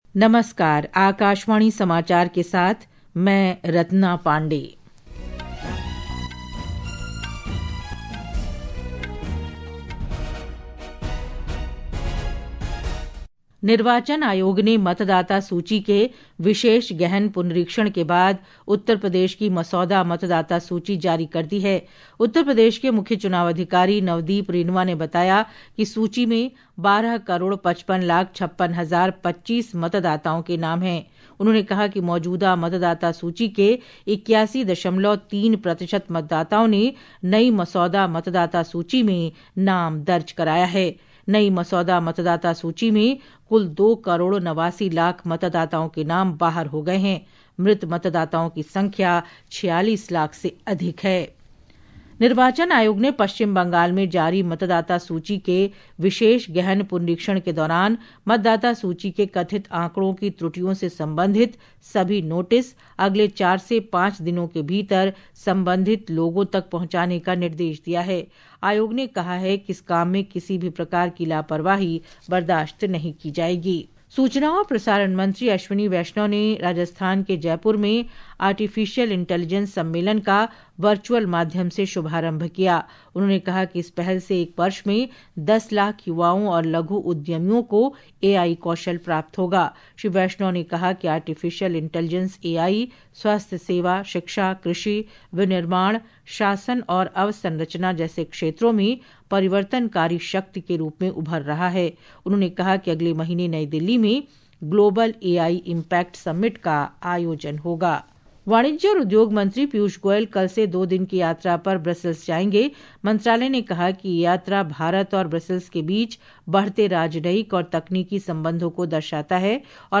National Bulletins
प्रति घंटा समाचार